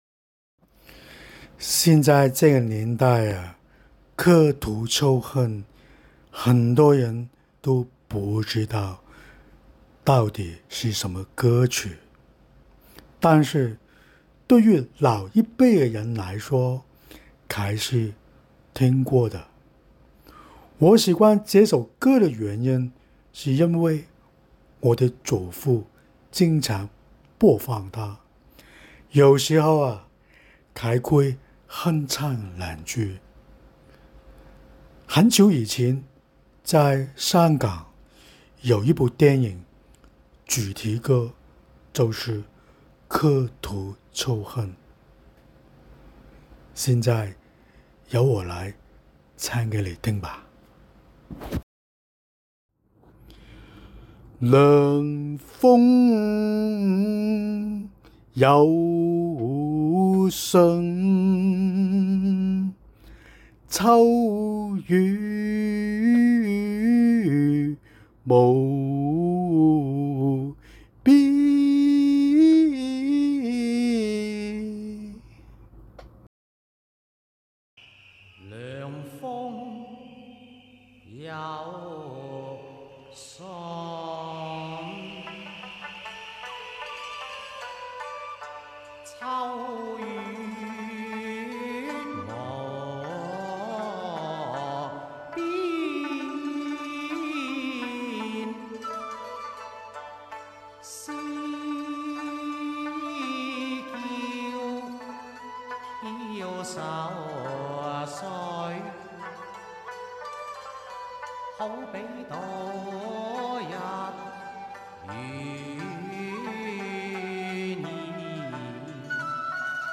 古樸风味情歌
南音是广东地区一种传统的说唱艺术，以粤语演唱，起源于清朝末年。